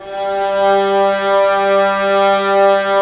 Index of /90_sSampleCDs/Roland L-CD702/VOL-1/STR_Vlas Bow FX/STR_Vas Sordino